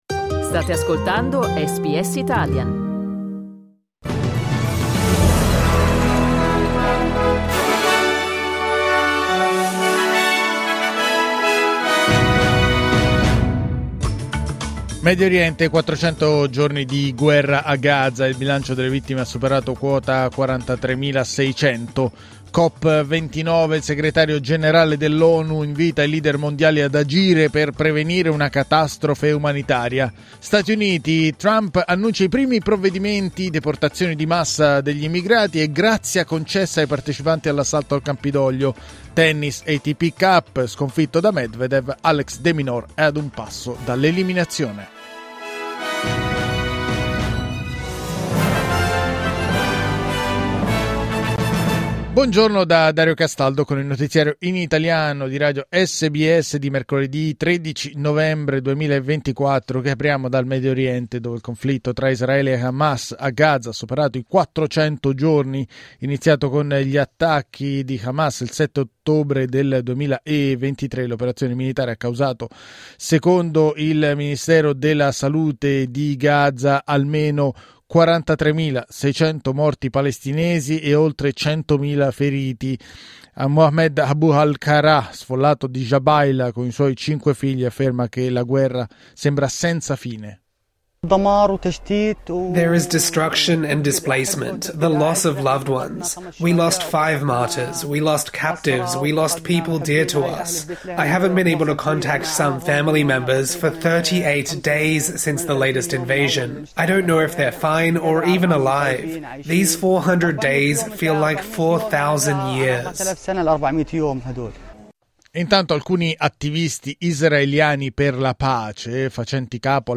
Giornale radio mercoledì 13 novembre 2024
Il notiziario di SBS in italiano.